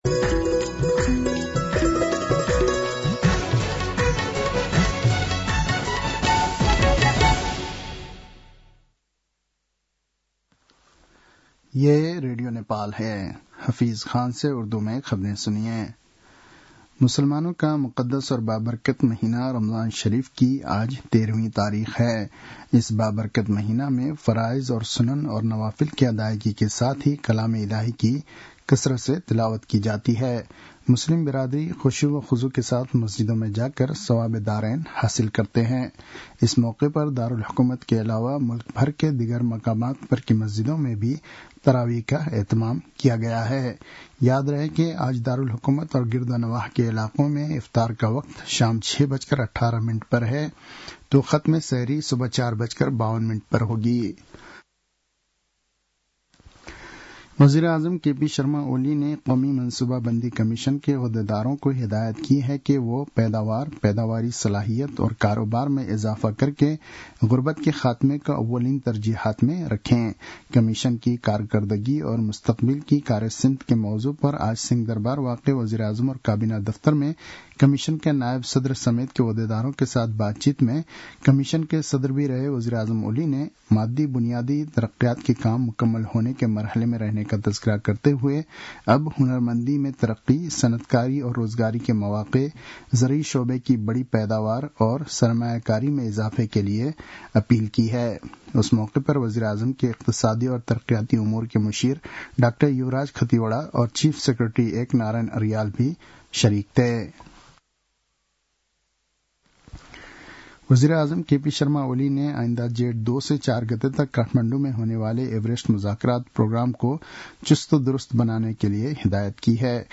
उर्दु भाषामा समाचार : १ चैत , २०८१